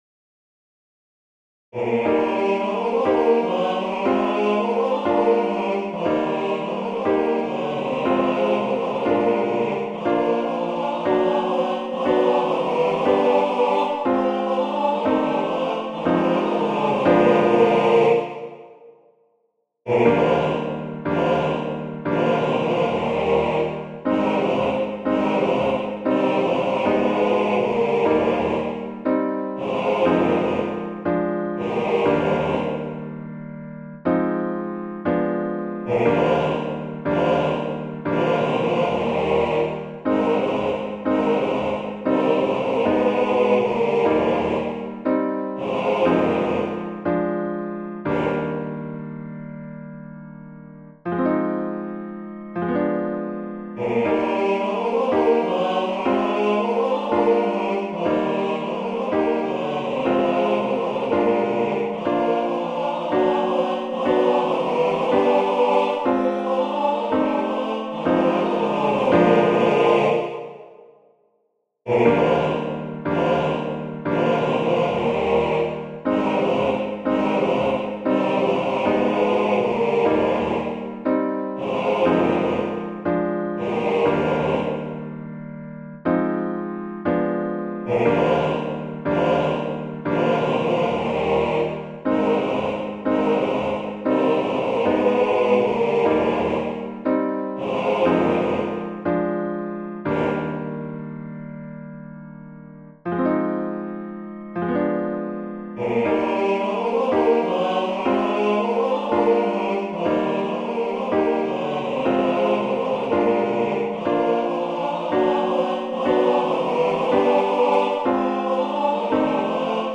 CHÓR
kabaretowego utworu